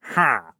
Minecraft Version Minecraft Version 1.21.5 Latest Release | Latest Snapshot 1.21.5 / assets / minecraft / sounds / mob / wandering_trader / no4.ogg Compare With Compare With Latest Release | Latest Snapshot